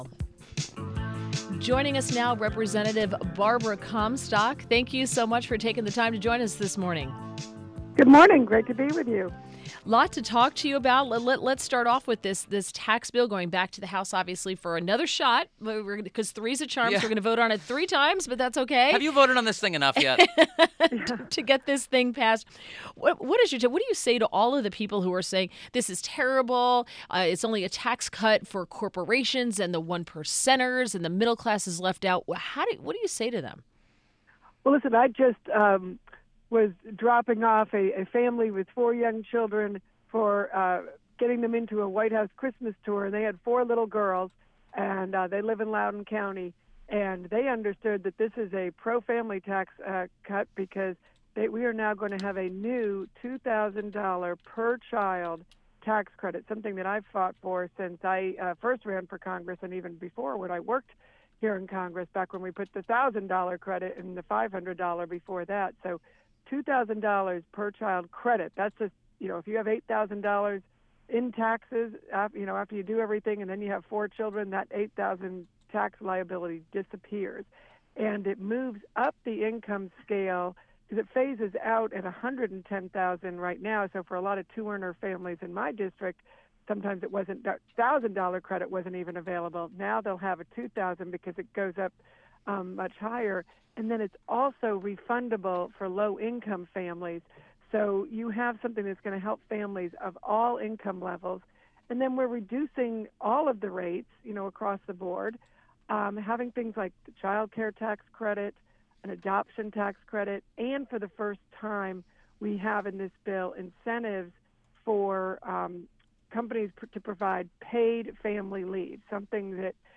INTERVIEW - REP. BARBARA COMSTOCK - R-VA